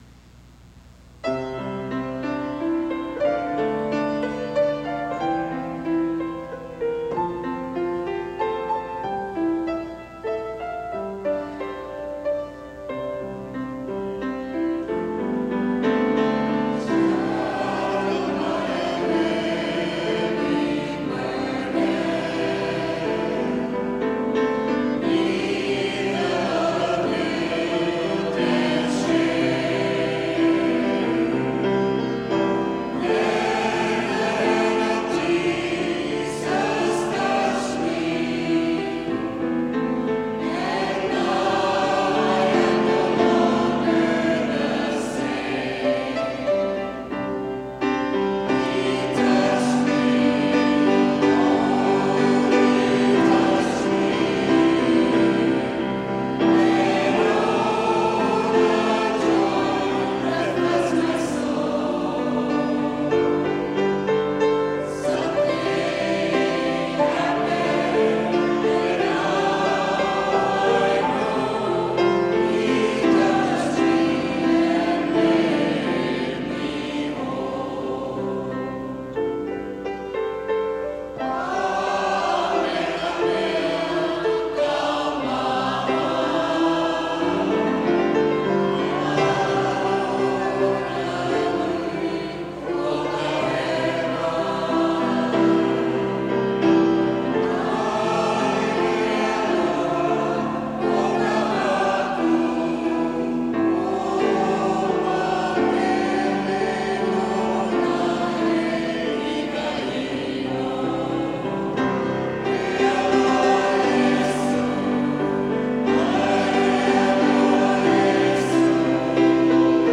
Enjoy an evening of Hawaiian-style church music by several Oahu parish choirs.
He Touched Me/He Aloha O Iesü  (Gaither) St. Joseph Choir
Take Up Your Cross  (Boltz) Our Lady Of Sorrows Voices of Faith Choir